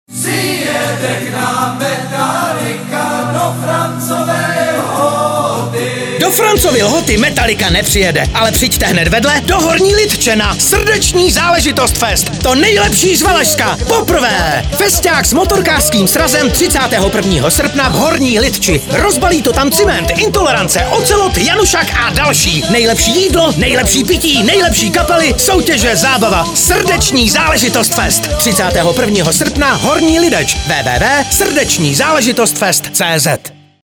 Audio pozvánka